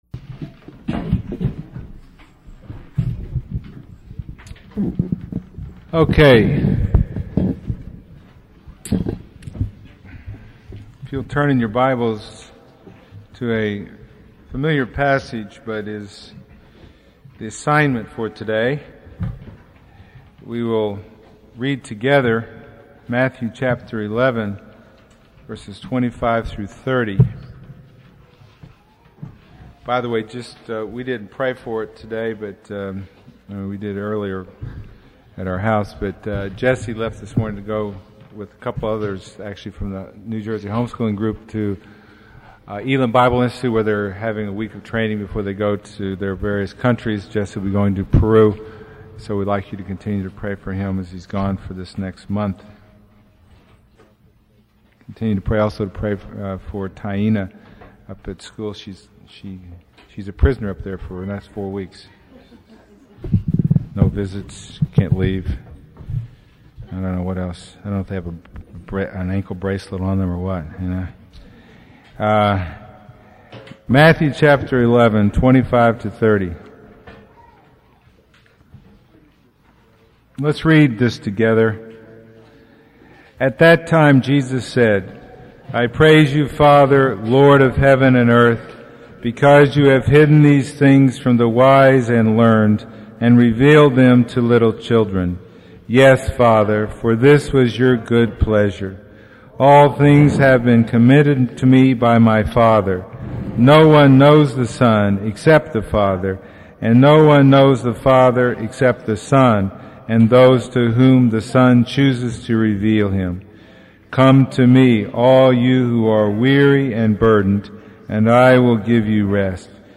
Passage: Matthew 11:25-30 Service Type: Sunday Morning